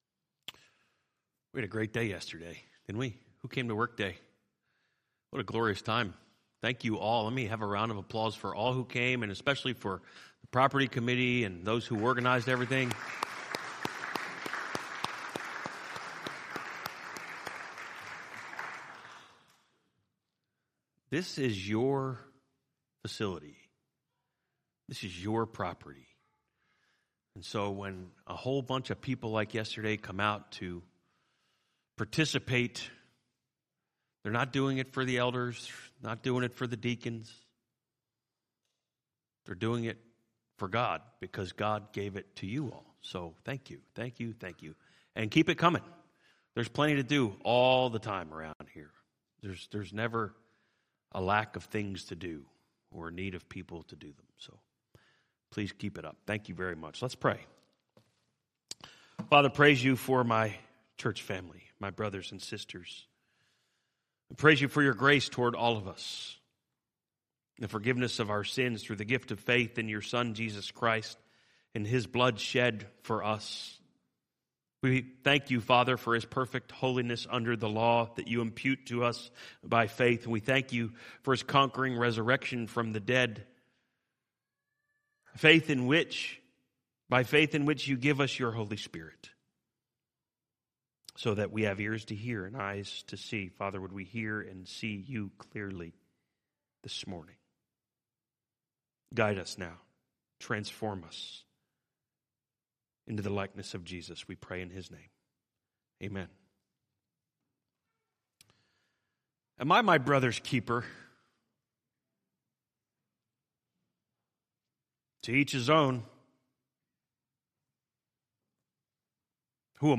A message from the series "Defending One Gospel."